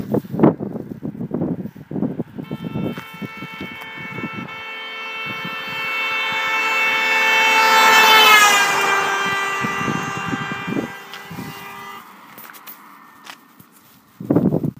Voiture mouvement
son voiture mvt.wav